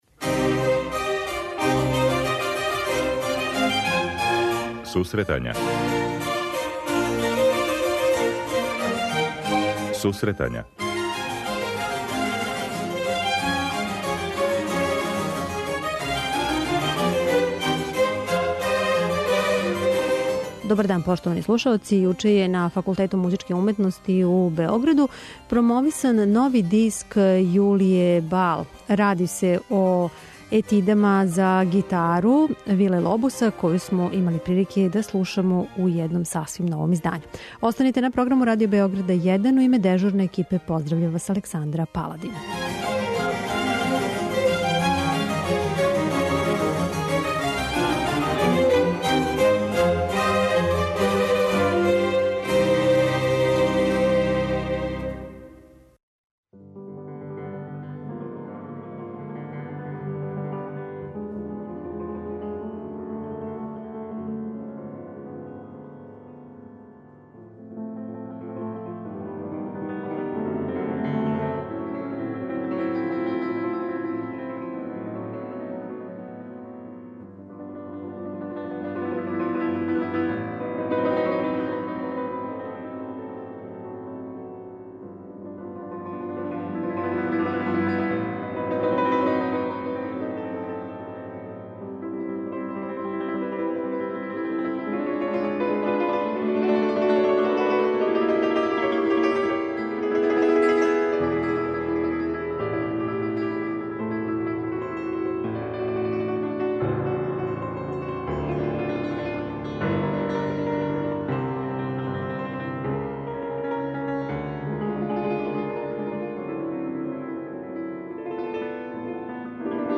На промоцији која је одржана на Факултету музичке уметности у Београду разговарали смо са уметницом о овом пројекту.